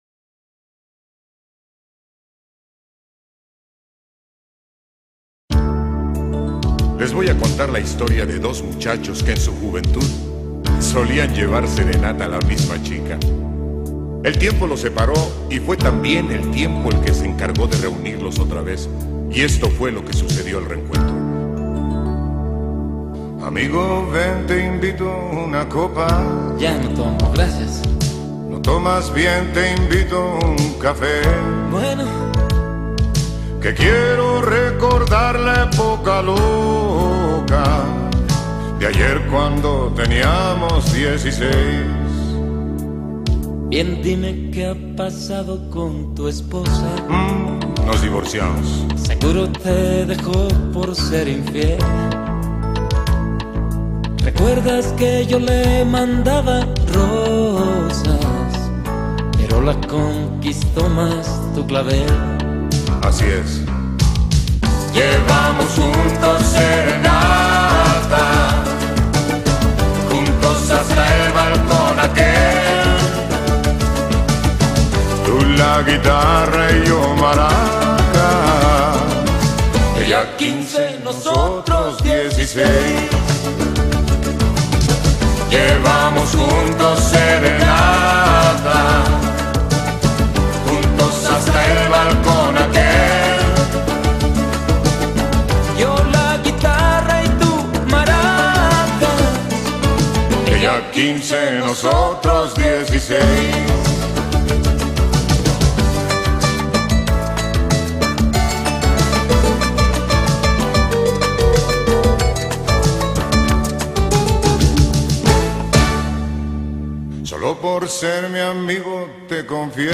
LBseTBrpL54_maracas.mp3